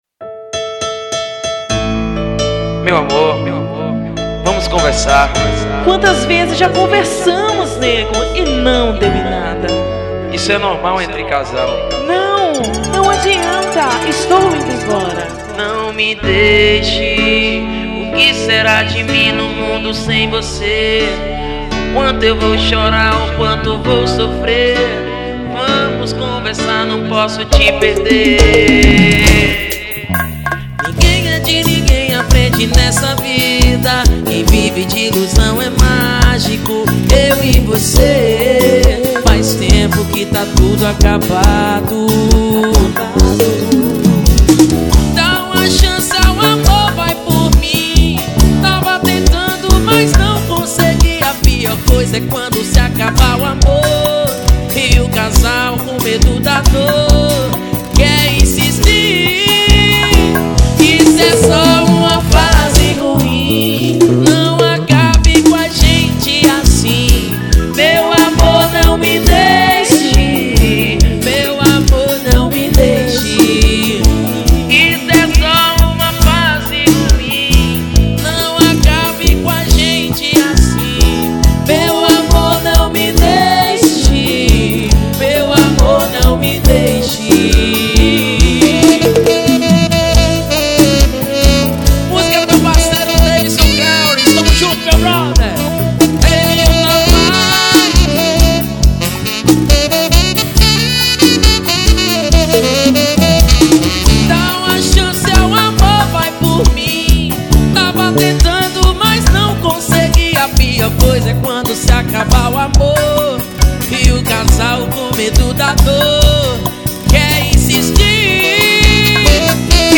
EstiloArrocha